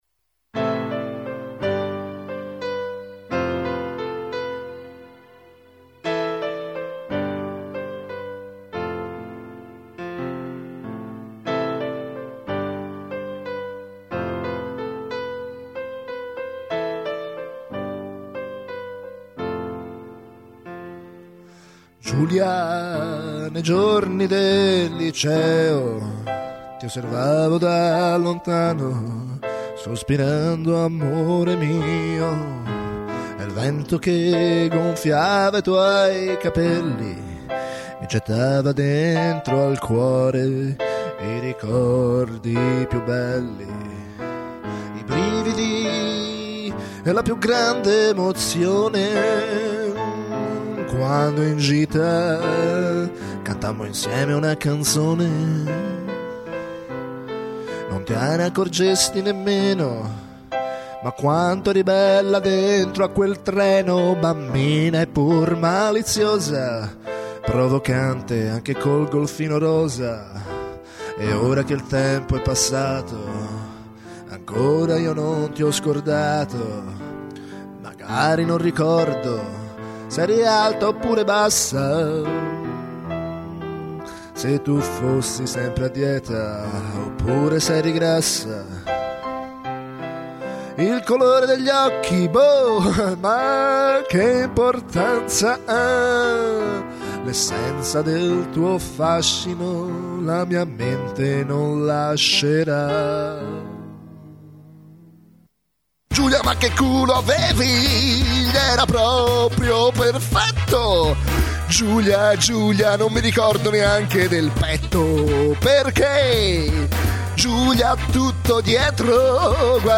Uno struggente brano d'amore